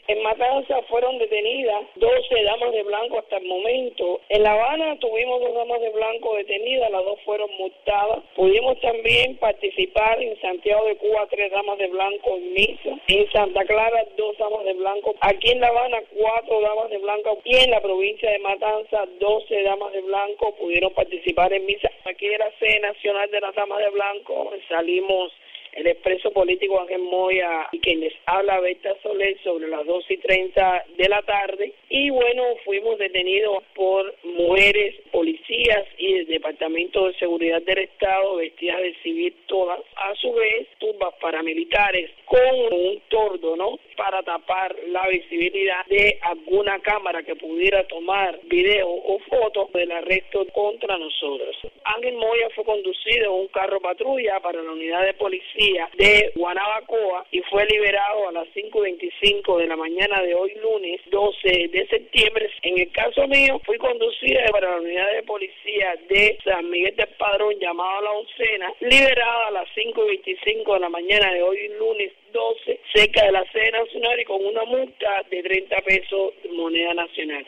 Declaraciones de Berta Soler a Radio Martí